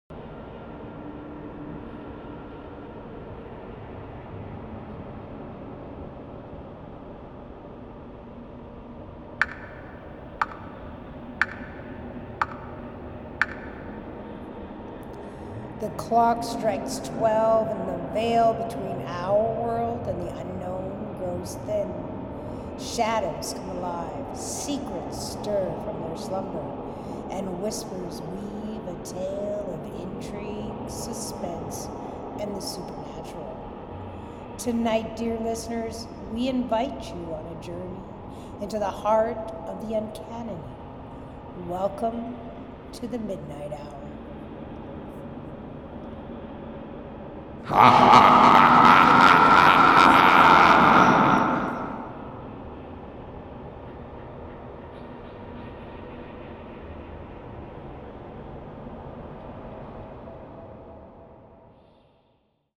Recording 3:  In this version I created a layered sounds with the addition of the wind